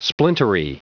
Prononciation du mot splintery en anglais (fichier audio)